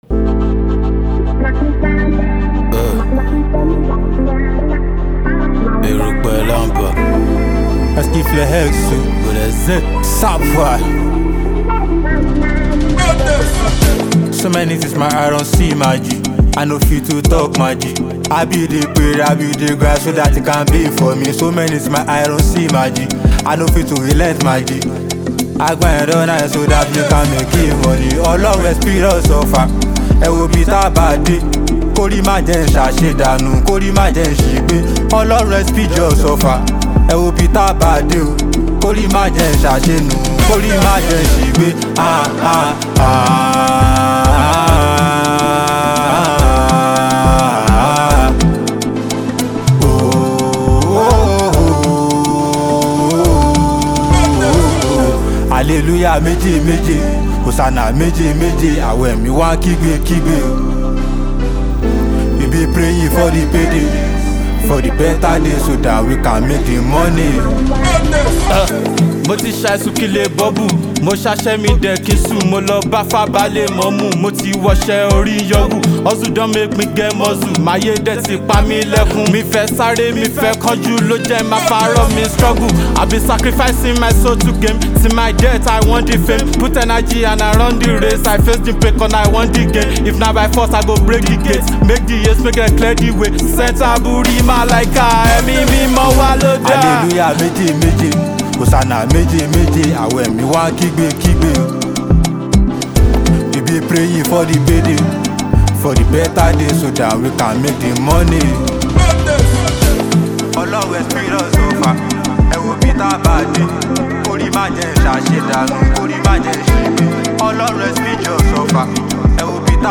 a trailblazer in the Afrobeats scene